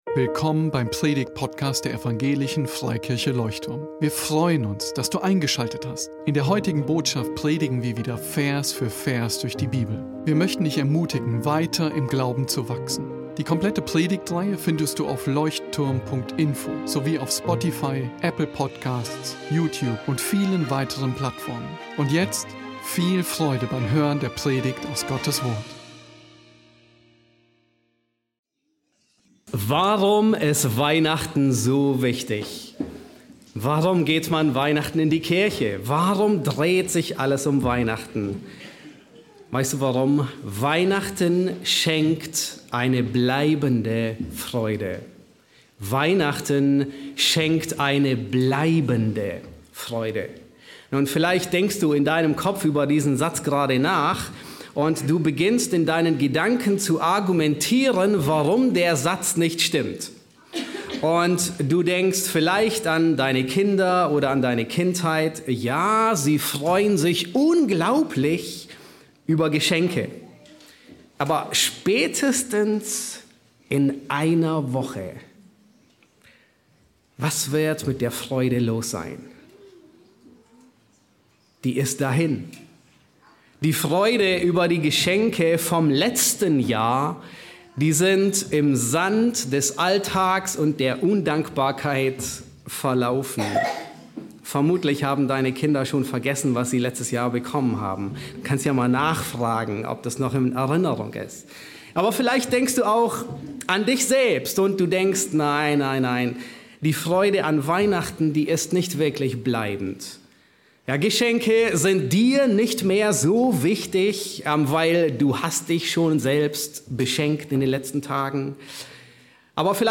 Leuchtturm Predigtpodcast Podcast